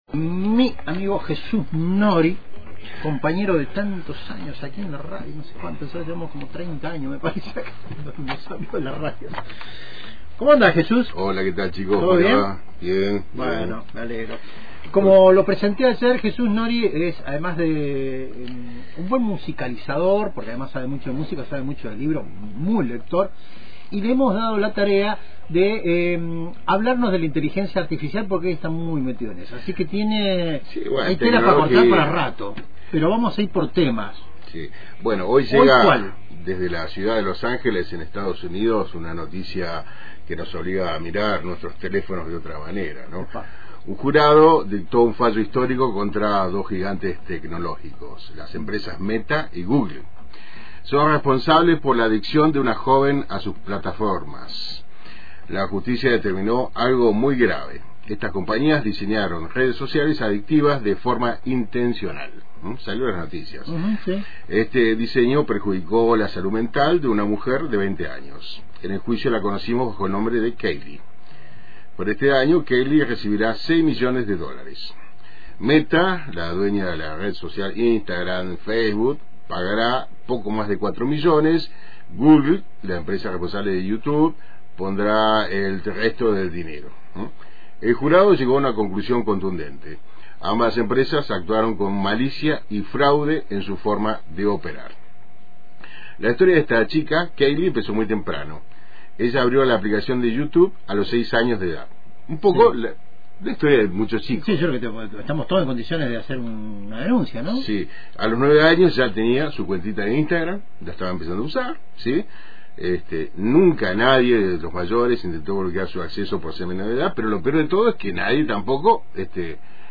Un fallo judicial en Los Ángeles volvió a encender el debate sobre el impacto de las redes sociales en la salud mental. En una entrevista radial, se analizó la condena contra Meta y Google por el daño causado a una joven que desarrolló una fuerte adicción a sus plataformas desde la infancia. El caso reabrió preguntas sobre el diseño deliberadamente adictivo de estas aplicaciones, el acceso temprano de niñas y niños a redes sociales y la falta de controles efectivos.